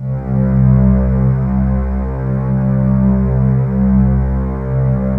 Index of /90_sSampleCDs/USB Soundscan vol.28 - Choir Acoustic & Synth [AKAI] 1CD/Partition D/09-VOCODING
VOCODINGC2-L.wav